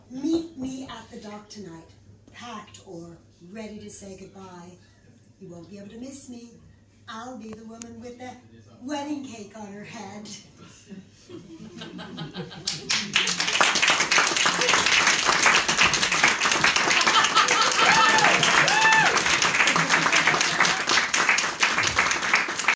Our first public Pop-Up Pub Theater, February 7th, 2025, at Bardello.
Applause